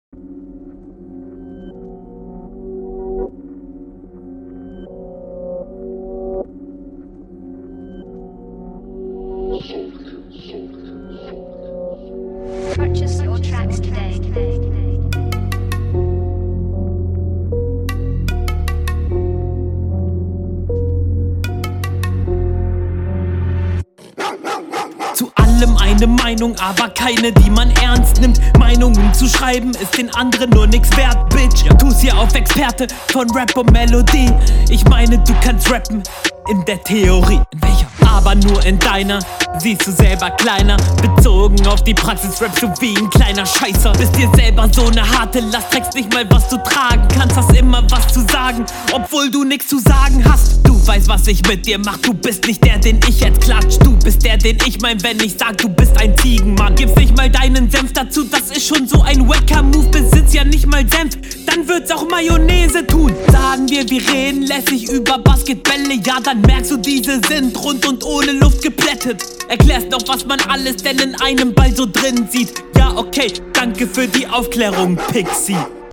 Flow: Echt stabil Text: Schön von oben herab ein paar miese Bars gedropt, disst alles …
Flow: Der Flow wirkt sehr unsicher meiner meinung und der stimmeinsatz hat was zwischen man …